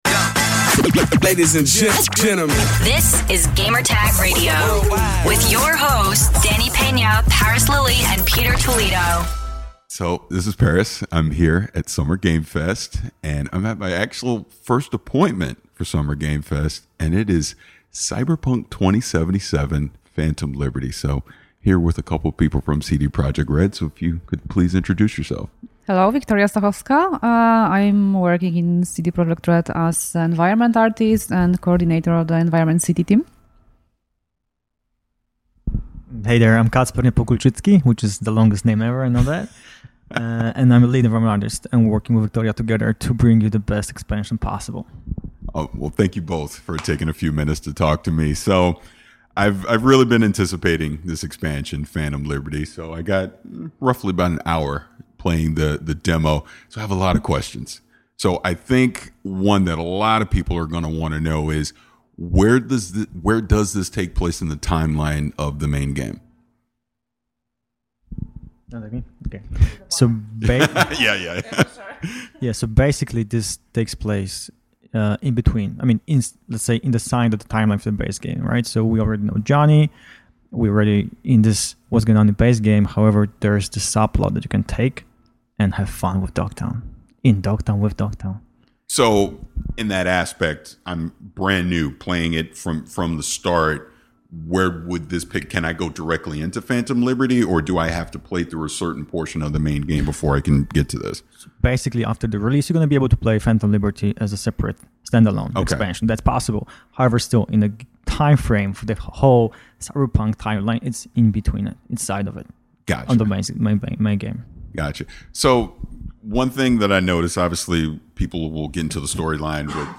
SGF23: Cyberpunk 2077: Phantom Liberty Interview